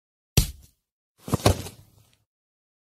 Вы можете скачать её леденящий душу смех, скрип дверей, удары молотка и другие жуткие звуковые эффекты в высоком качестве.
Звук мощи Гренни Удар игрока ее дубинкой